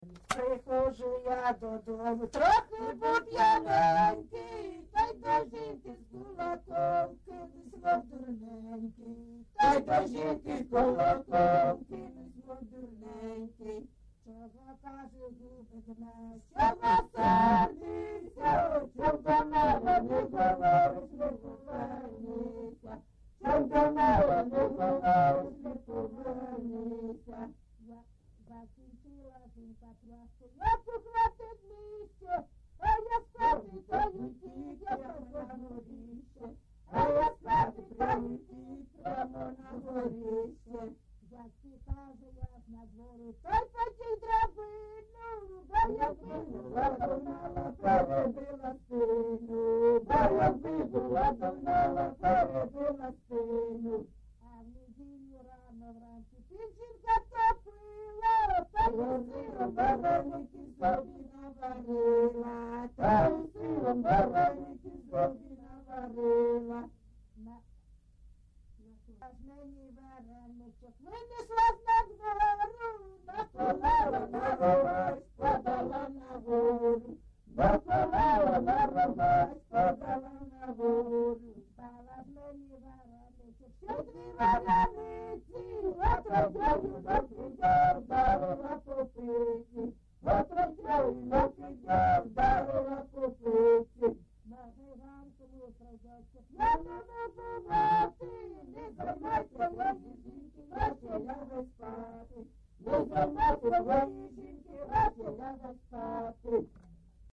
ЖанрЖартівливі
Місце записус. Олексіївка, Великоновосілківський (Волноваський) район, Донецька обл., Україна, Слобожанщина